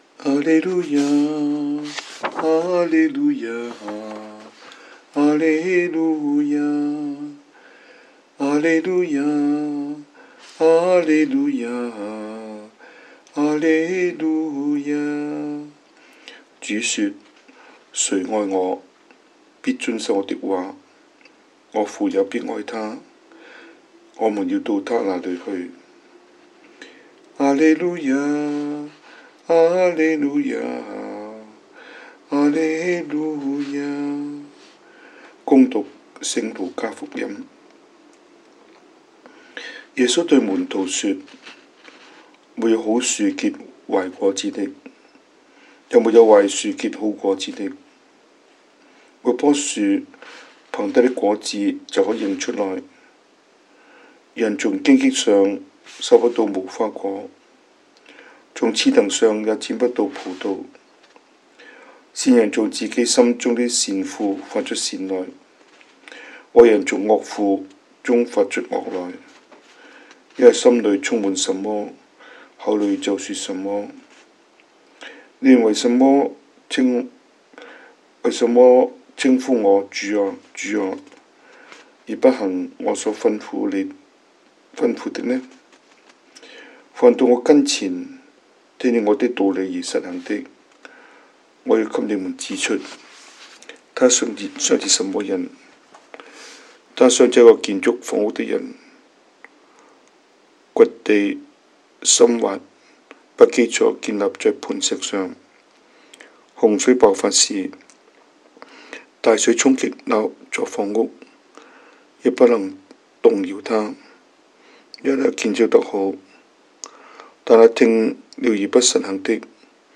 神父講道